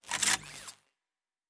Sci-Fi Sounds / Weapons
Weapon 12 Reload 1 (Laser).wav